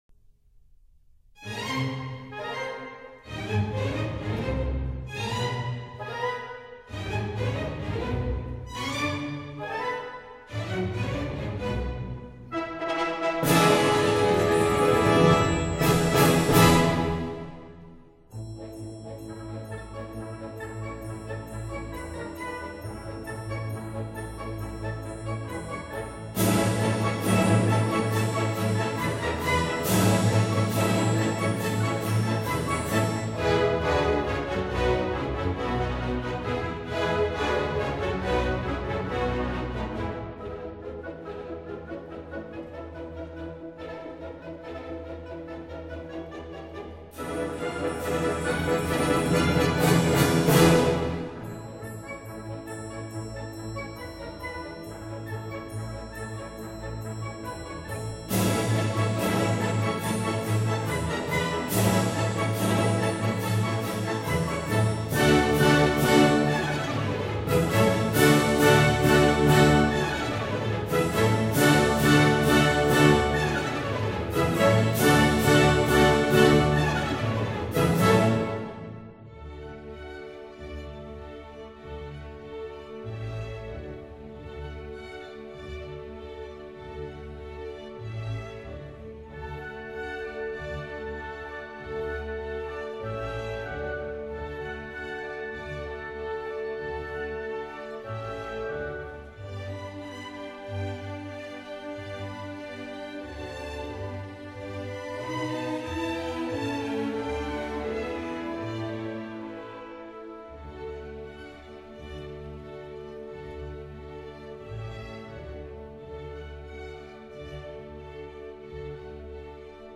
Galop general
加洛普 <02:45>